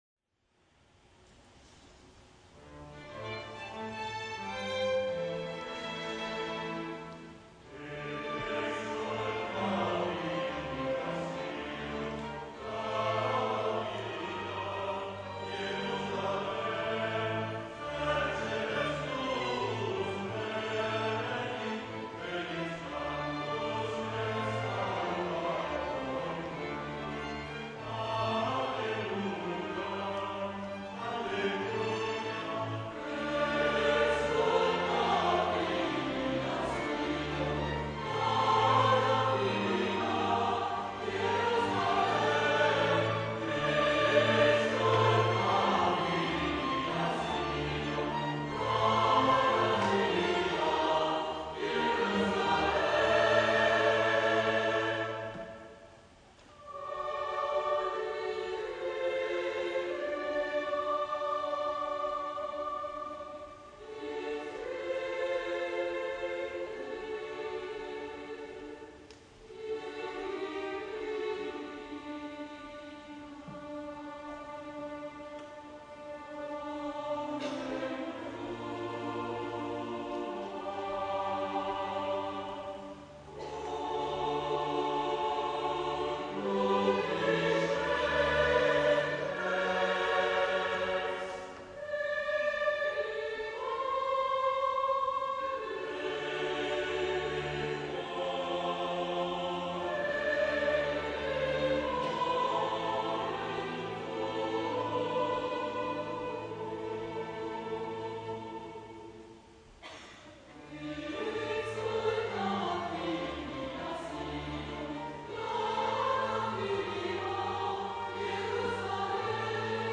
· Contenuto in Choir or Solo Voices + Instrumental ensemble